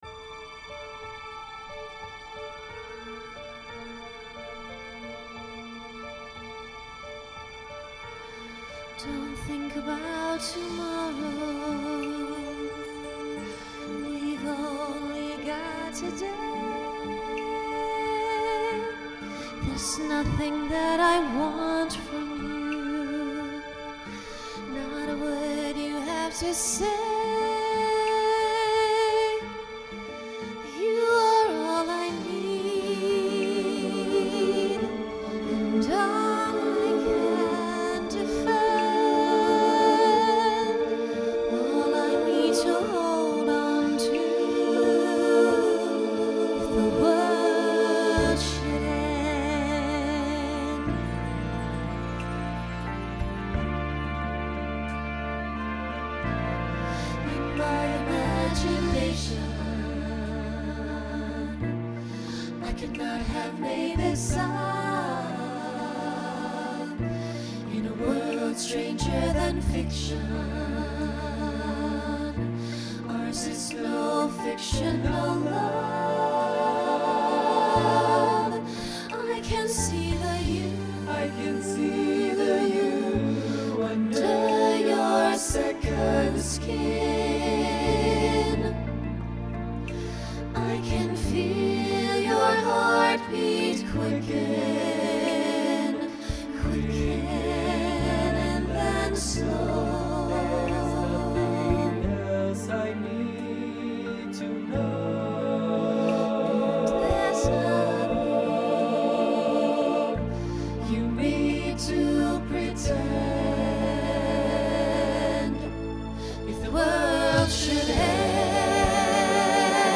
Genre Broadway/Film Instrumental combo
Function Ballad Voicing SATB